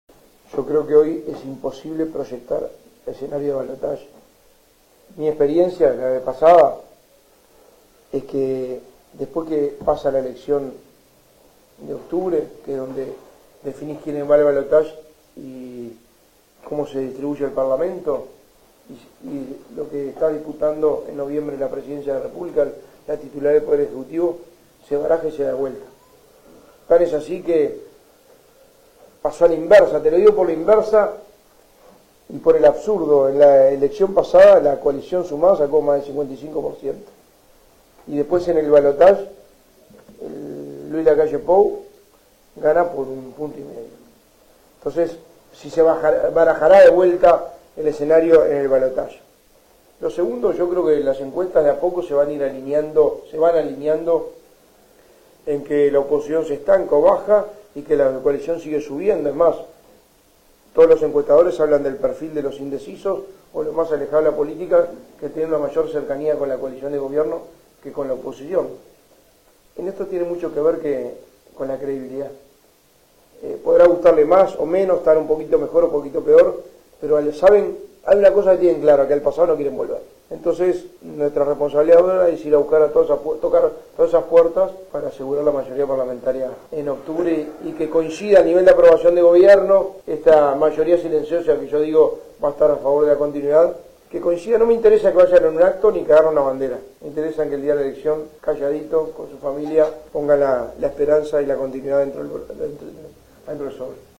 El candidato a la Presidencia por el PN, Álvaro Delgado, realizó este jueves una visita a San José de Mayo, brindando una rueda de prensa en el Hotel Centro, antes de mantener una reunión con la dirigencia política departamental, en la Casa del Partido.